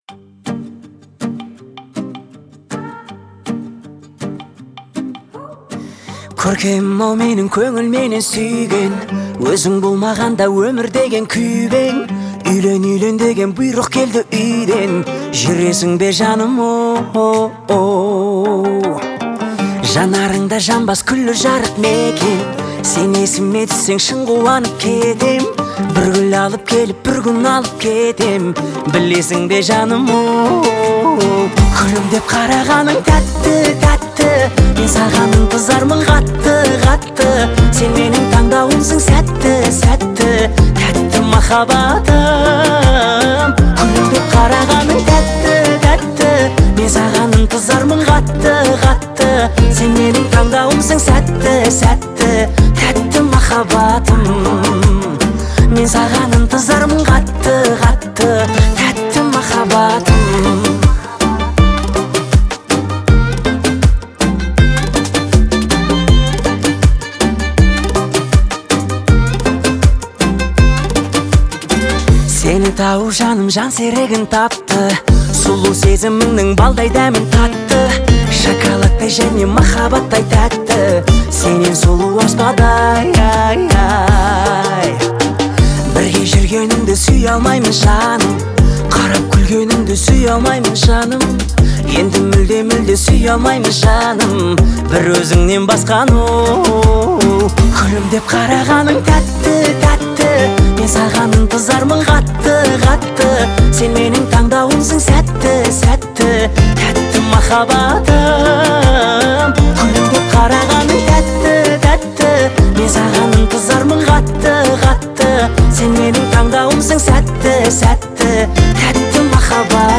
• Жанр: New Kaz / Казахские песни